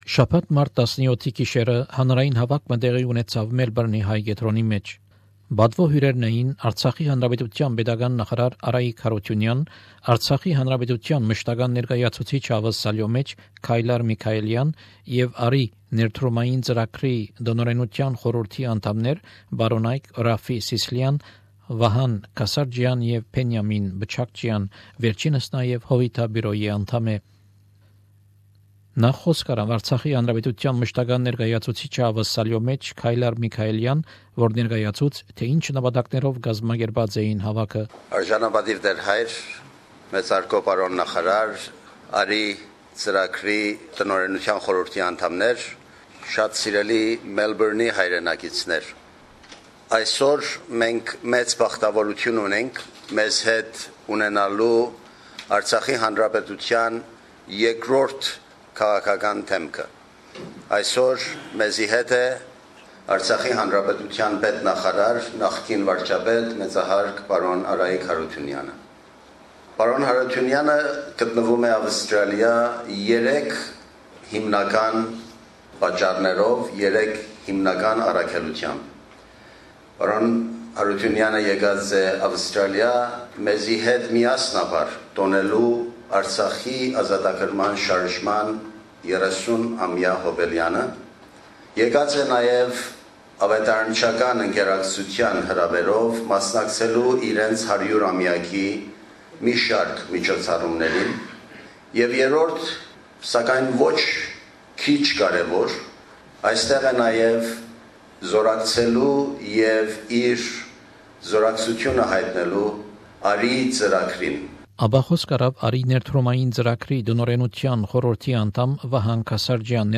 Շաբաթ Մարտ 17-ի գիշերը հանրային հաւաք մը տեղի ունեցաւ Մելպըրնի Հայ Կեդրոնին մէջ: